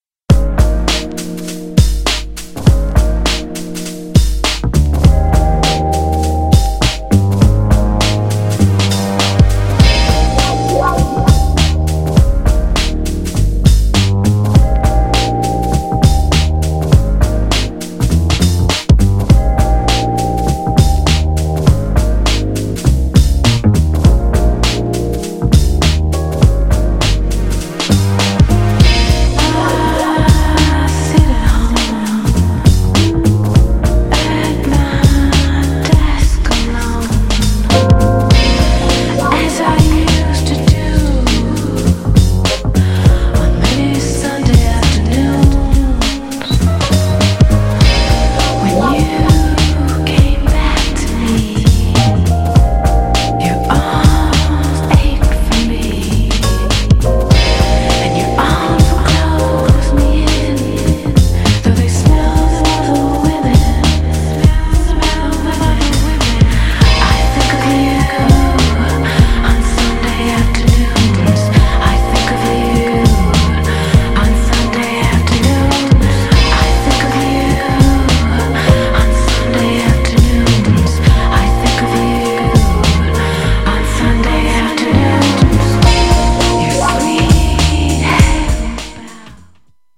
JAZZYなチルアウトチューン!!
GENRE House
BPM 96〜100BPM
# JAZZY
# ダウンテンポ # ピアノが美しい